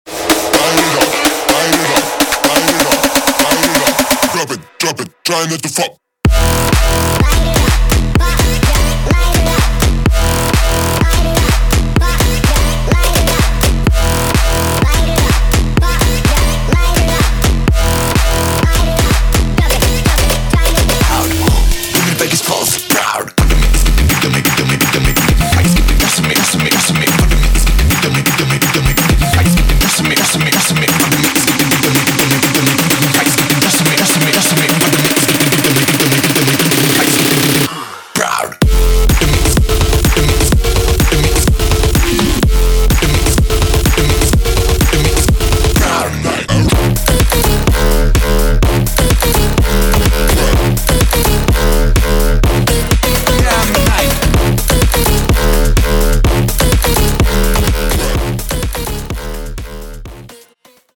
• 凶猛强悍的贝斯循环: 让你作品爆发出强烈的贝斯能量，引爆舞池气氛。
• 种类丰富的鼓点循环: 多样兼容的鼓点素材，为你的贝斯浩室节奏奠定坚实基础。
• 创意抓耳的合成器循环: 引人入胜的旋律线条，让你的作品脱颖而出。